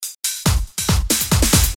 部落恍惚大鼓 Var 1
描述：部落Trance鼓Var 1 Trance Electro Electronic Beat
标签： 140 bpm Trance Loops Drum Loops 295.48 KB wav Key : Unknown
声道立体声